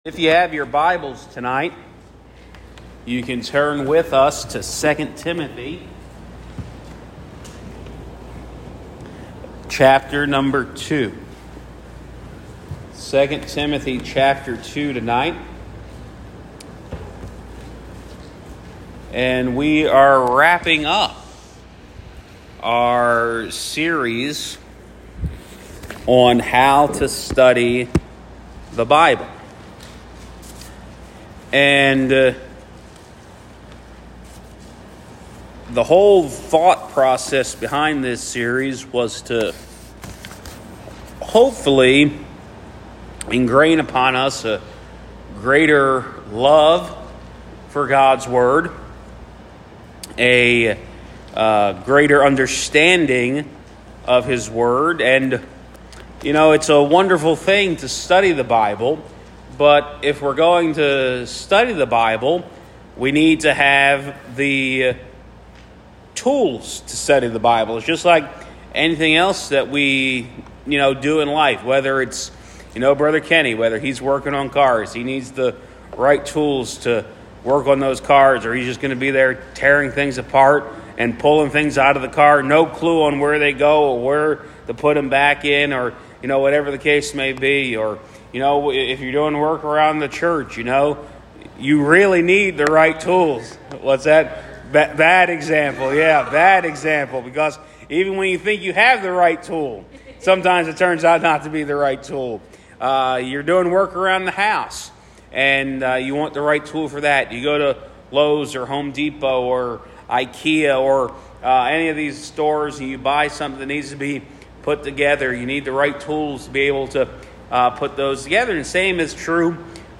Wednesday Evening Bible Study
Guest Speaker